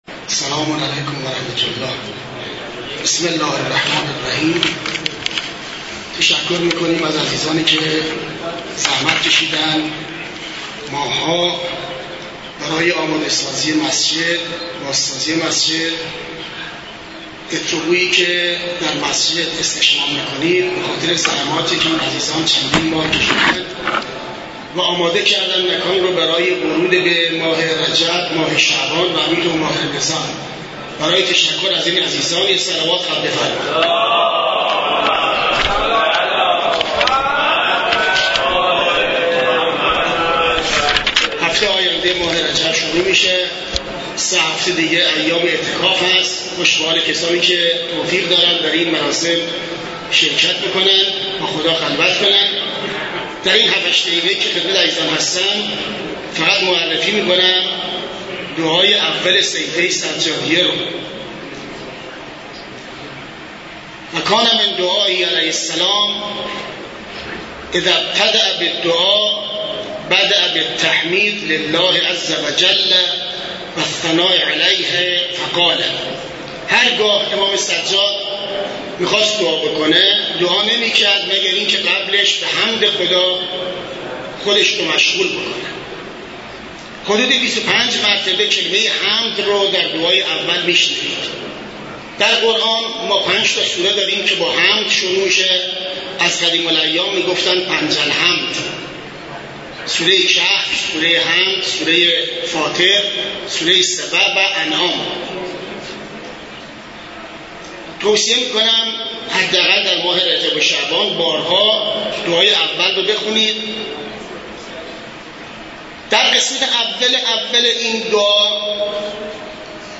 سخنرانی
در شرح دعای اول صحیفه سجادیه در مسجد دانشگاه کاشان برگزار گردید.